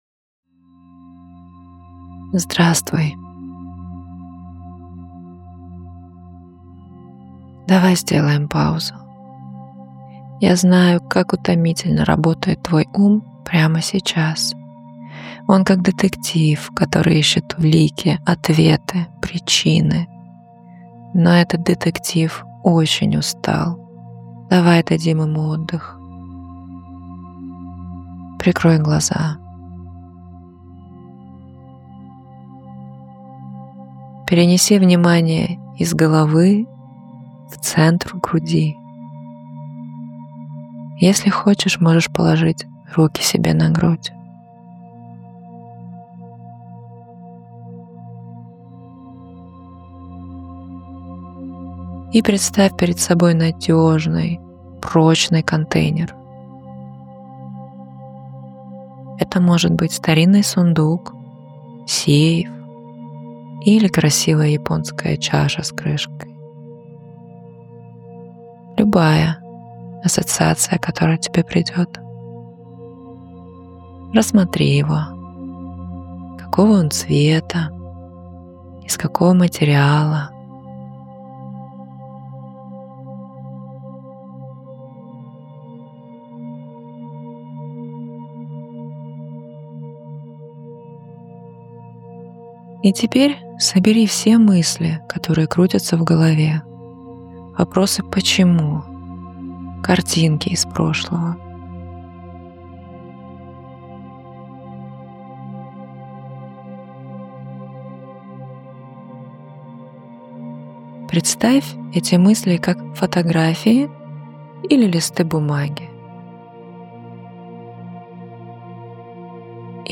meditaciya-kak-ostanovit-navyazchivye-mysli.mp3